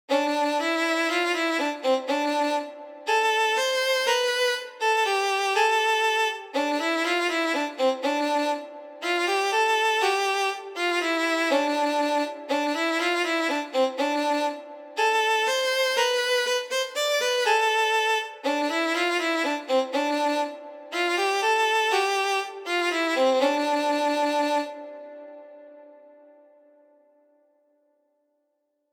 イントロ
luflen-violin-1.mp3